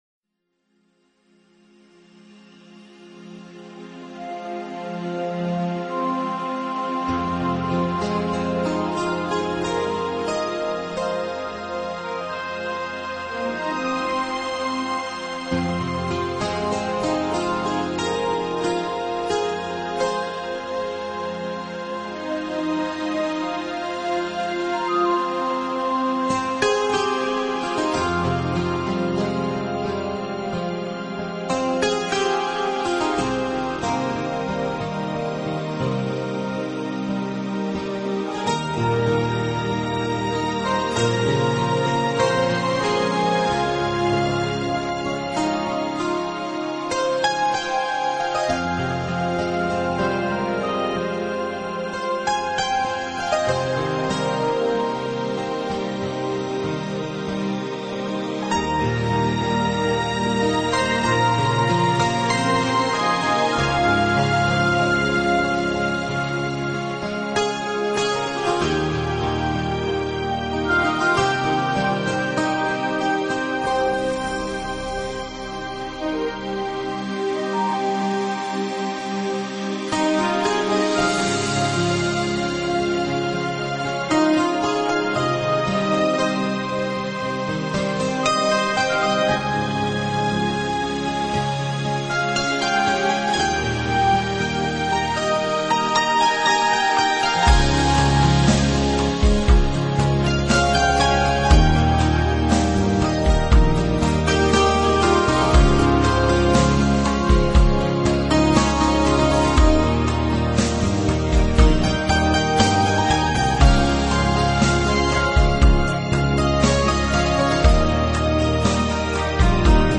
专辑类别： 乐曲专辑1CD
这是一张纯正的New Age专辑。
专辑中的作品力求做到简单明了，以键盘为主，仅用几件必须的乐器加以伴奏。